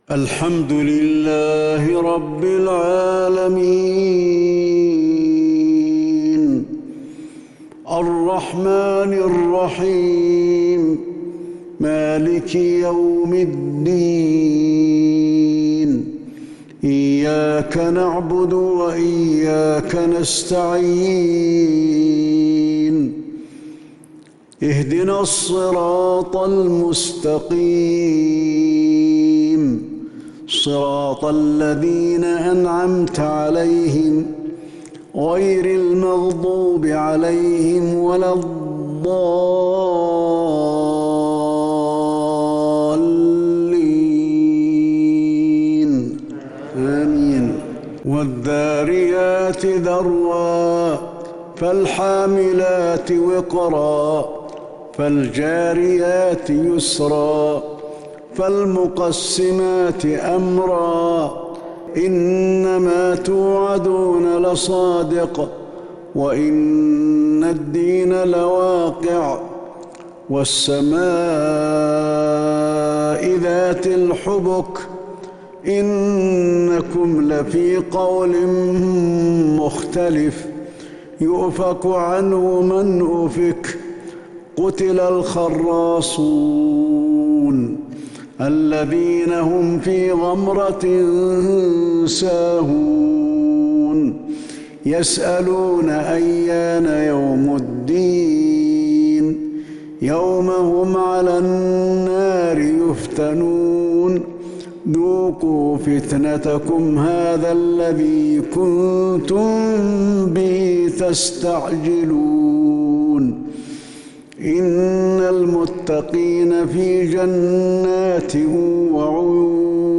صلاة العشاء للشيخ علي الحذيفي 9 ربيع الأول 1442 هـ
تِلَاوَات الْحَرَمَيْن .